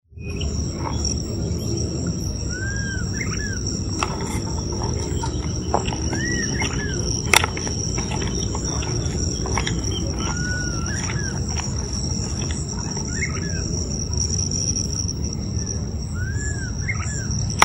Swainson´s Flycatcher (Myiarchus swainsoni)
Location or protected area: Reserva Natural Urbana La Malvina
Condition: Wild
Certainty: Recorded vocal